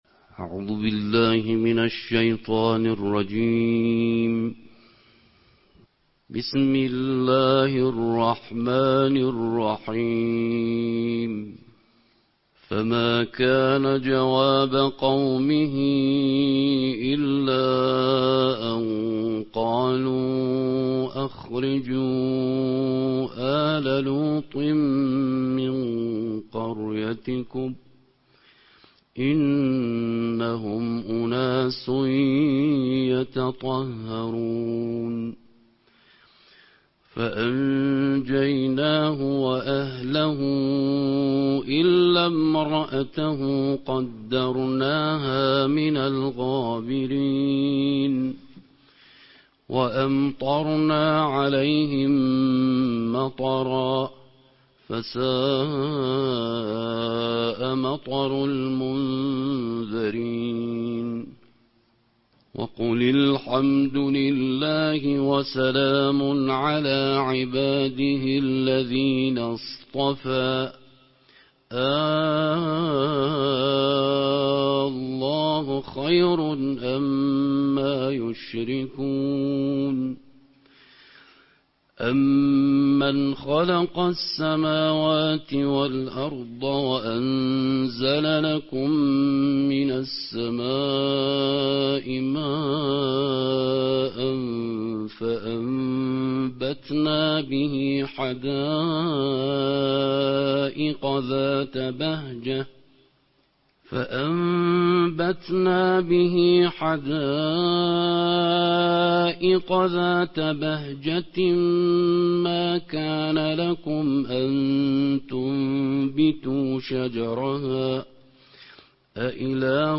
نړیوال قارِيان ،د قرآن کریم د شلمې(۲۰) سپارې یا جزوې د ترتیل قرائت